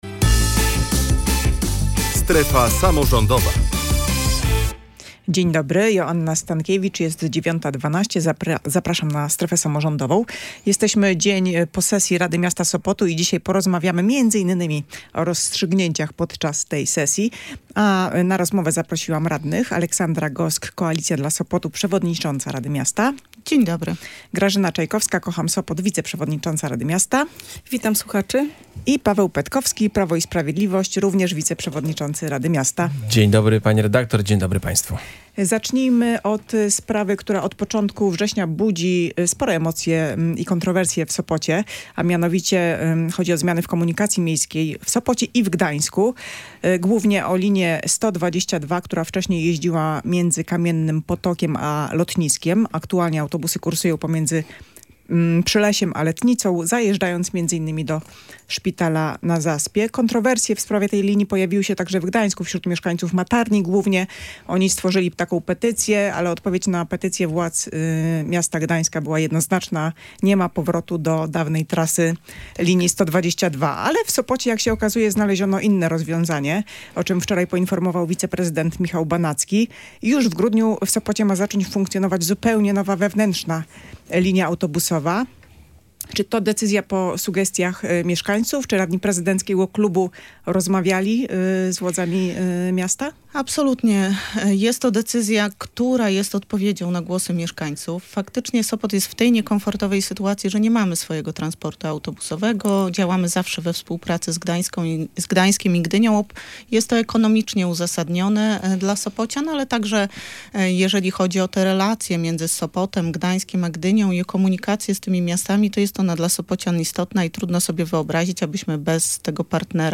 Na ten temat dyskutowali goście audycji „Strefa Samorządowa”